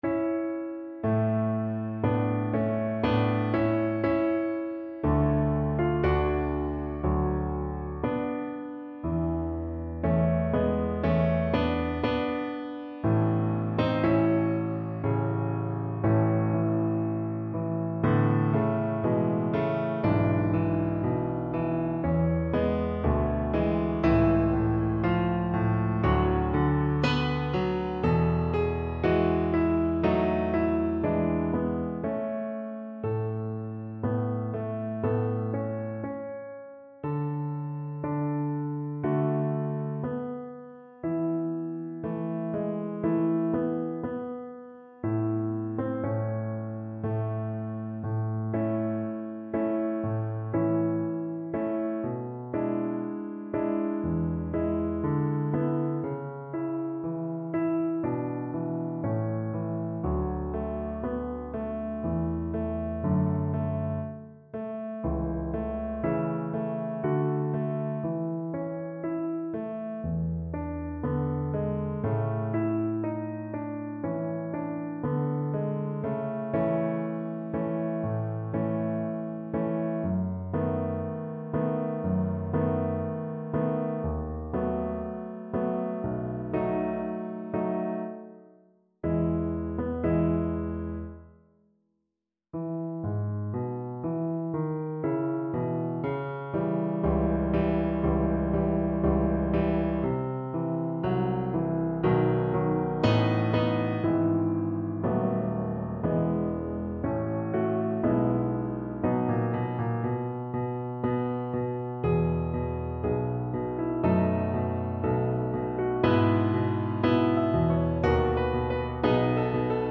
Adagio = c. 60
Classical (View more Classical Cello Music)